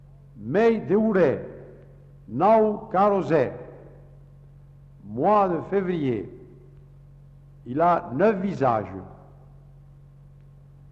Aire culturelle : Couserans
Genre : forme brève
Type de voix : voix d'homme
Production du son : récité
Classification : proverbe-dicton